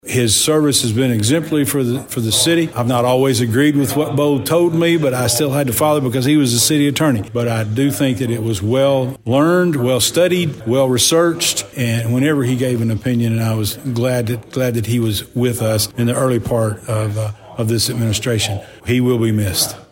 At Monday Night’s Dresden City Board meeting, City Attorney Beau Pemberton’s retirement was accepted.
Mayor Mark Maddox reflected on Mr. Pemberton’s counsel.